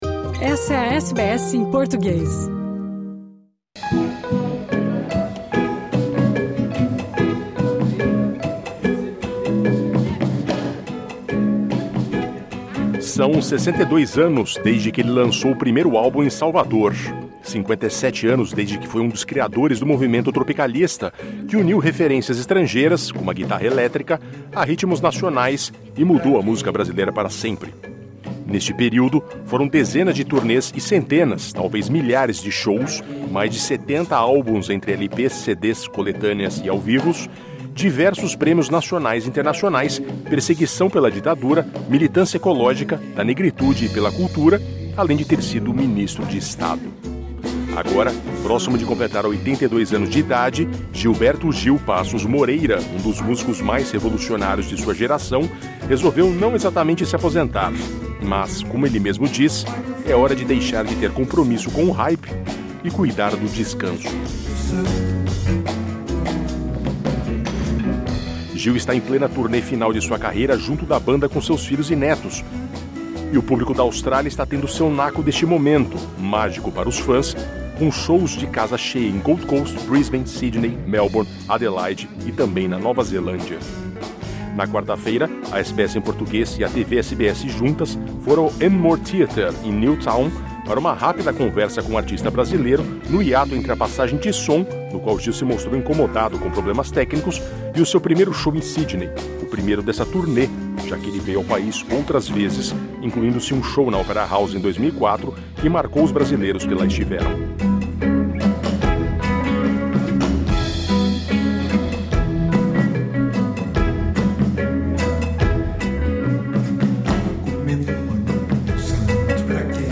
Conversamos com o artista brasileiro durante os preparativos para um de seus shows em Sydney, parte da última grande turnê de sua carreira. Nesta entrevista, ele afirma que realizou todos os sonhos de infância possíveis "de diversas maneiras", elogia a qualidade dos músicos contemporâneos e reafirma a curiosidade por conhecer mais dos australianos aborígenes.